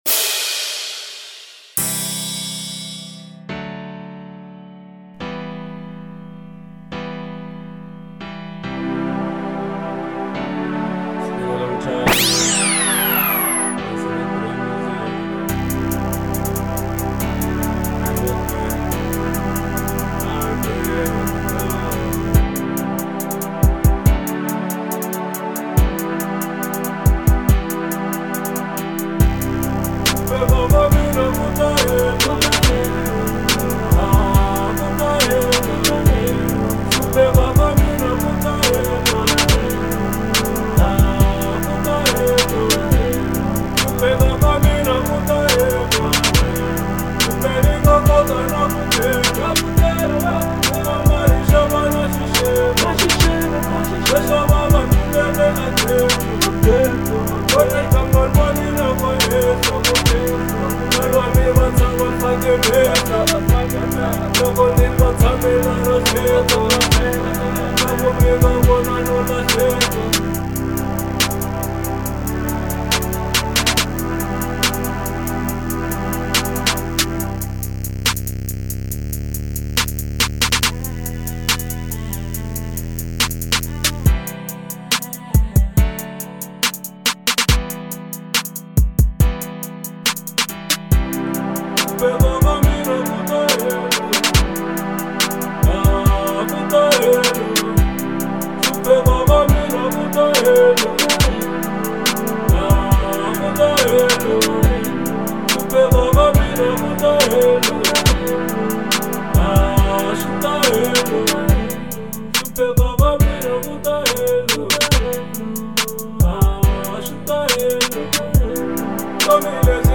03:15 Genre : Trap Size